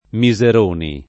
[ mi @ er 1 ni ]